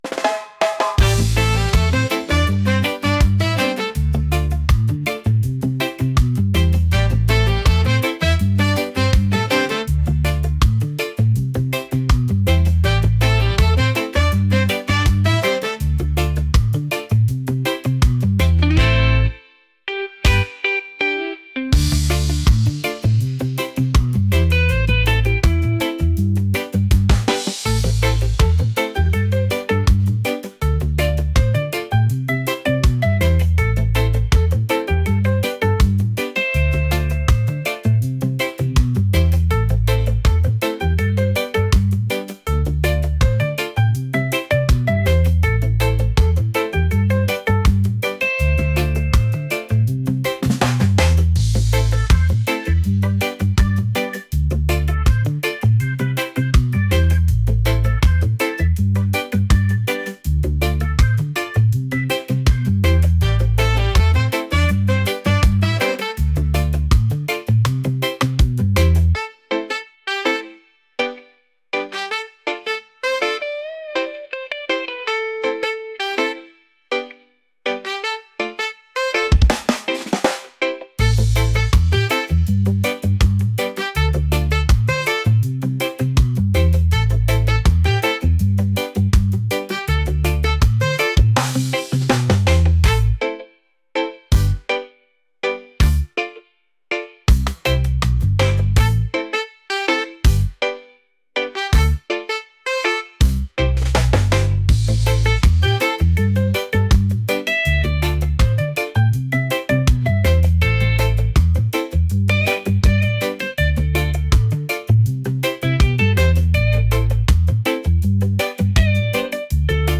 funky | reggae